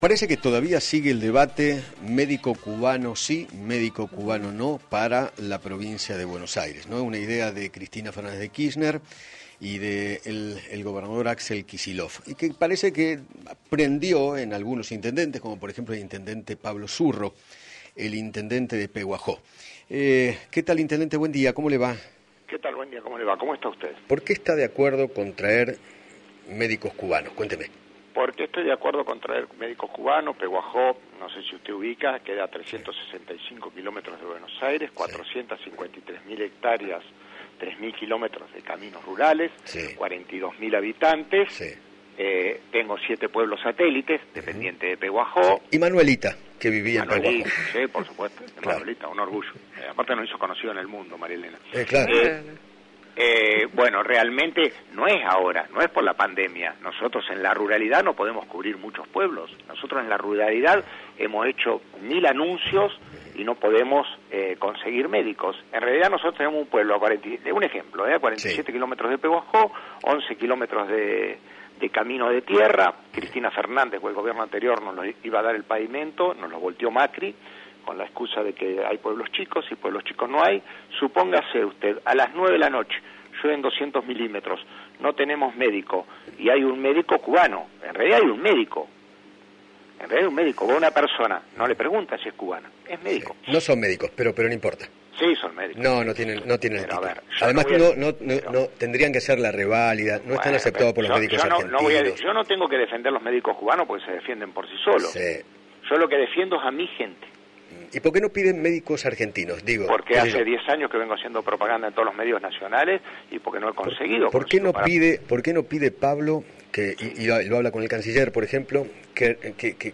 Pablo Zurro, Intendente de Pehuajó, dialogó con Eduardo Feinmann sobre la polémica llegada de médicos cubanos al país.